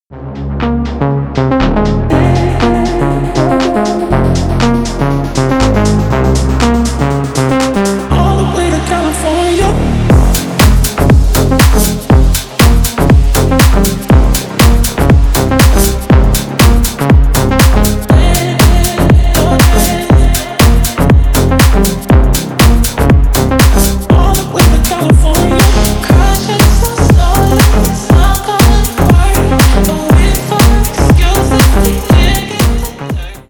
Танцевальные
клубные # громкие